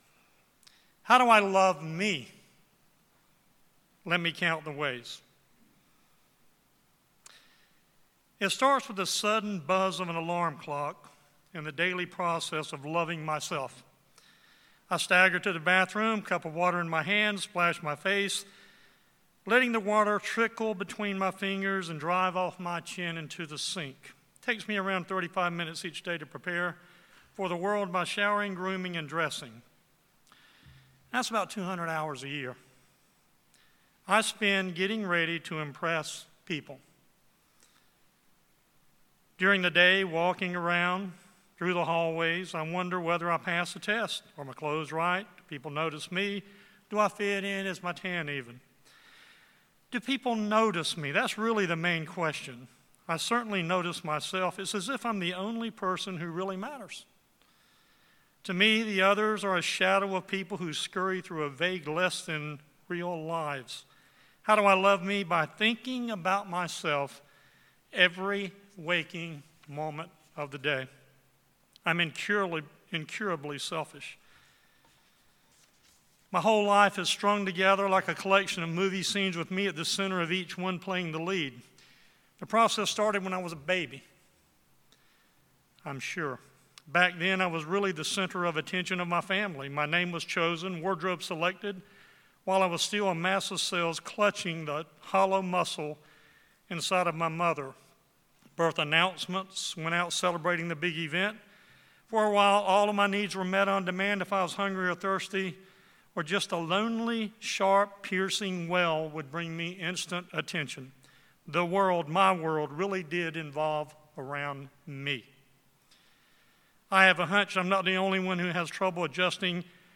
Sermons
Given in Raleigh, NC